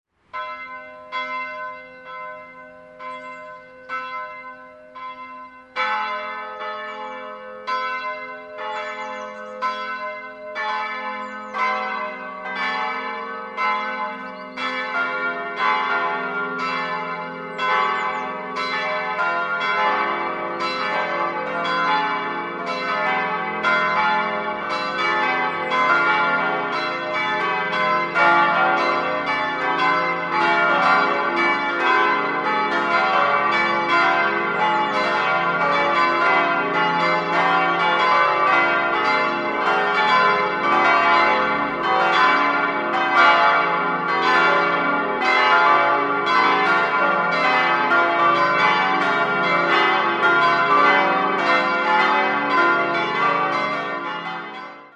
5-stimmiges ausgefülltes D-Moll-Geläute: d'-f'-g'-a'-c''
Die Glocken hängen völlig offen in der geräumigen Glockenstube, wodurch die Qualität nicht richtig zur Geltung kommen kann. Jedoch läuten alle fünf Glocken an Holzjochen in einem Holzglockenstuhl.